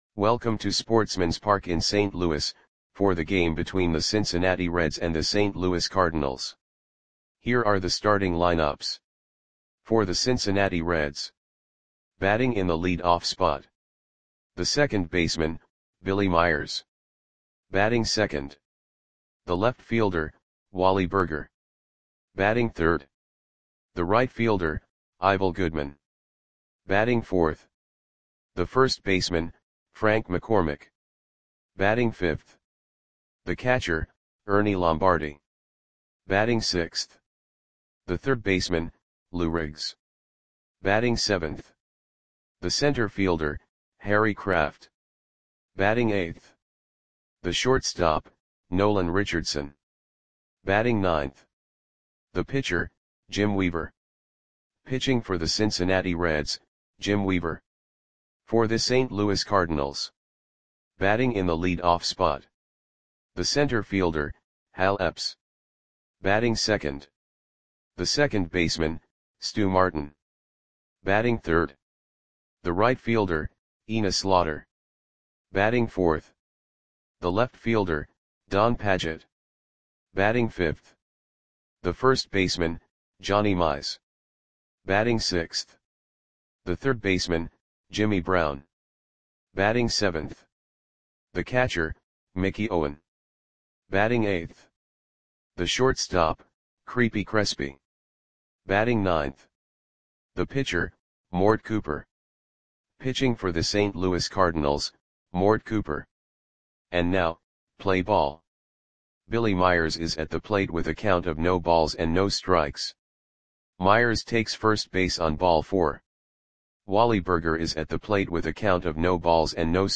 Audio Play-by-Play for St. Louis Cardinals on September 28, 1938